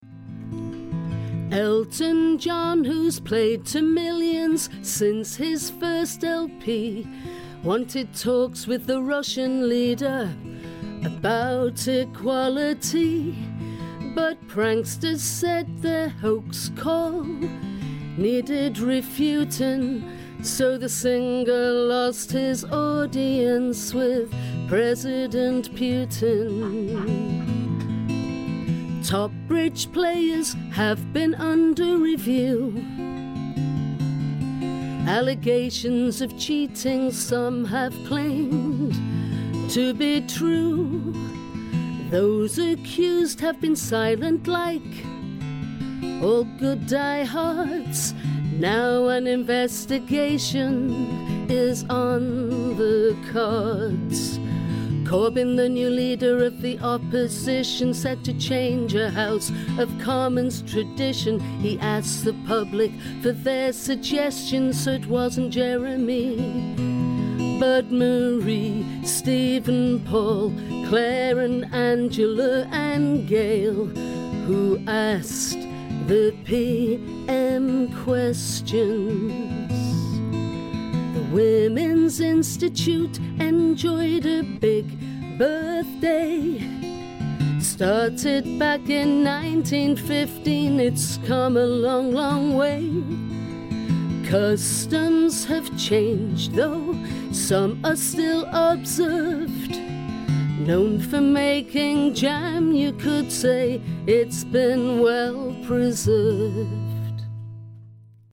this week's news in song